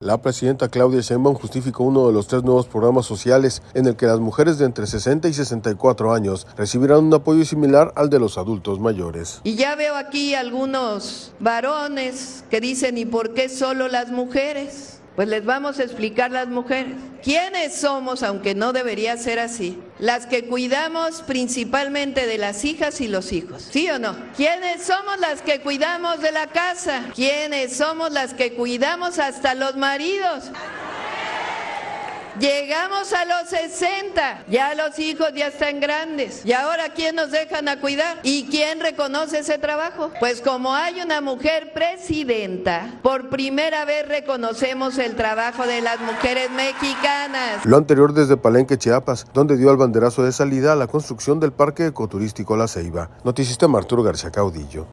Lo anterior desde Palenque, Chiapas, donde dio el banderazo de salida a la construcción del Parque Ecoturístico La Ceiba.